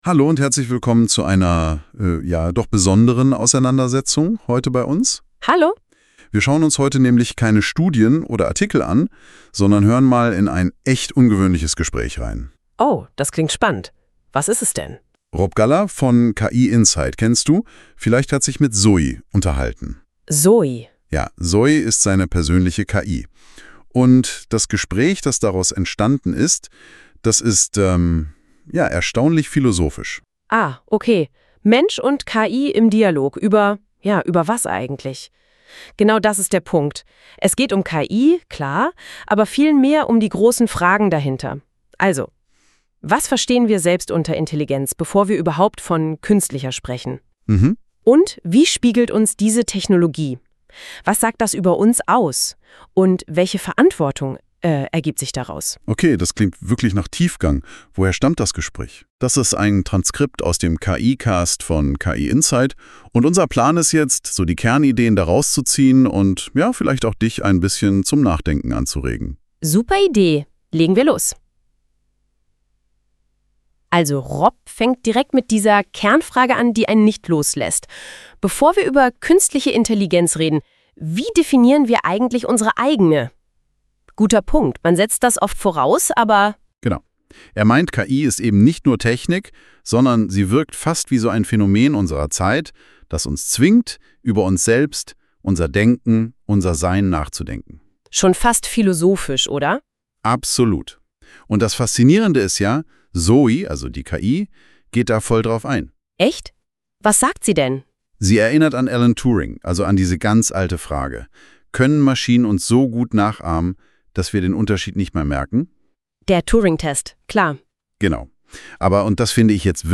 100 % KI erzeugter Podcast